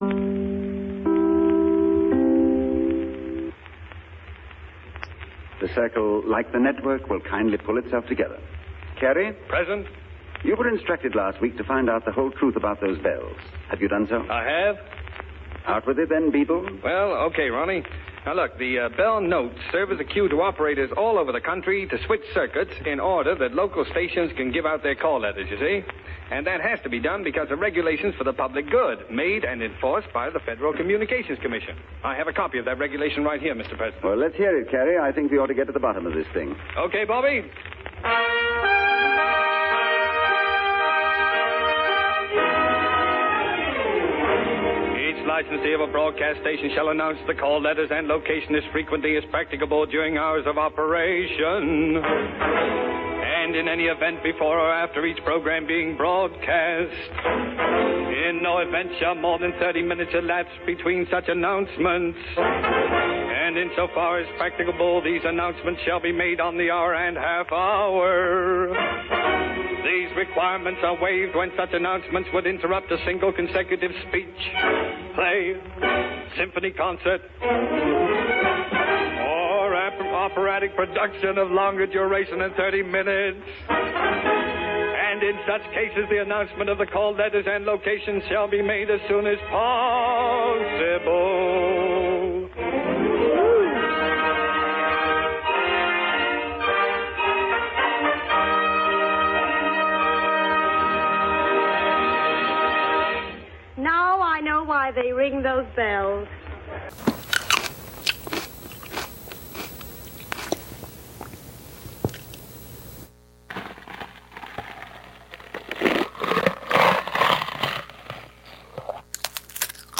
Radio Theatre: Family Dinner (Audio)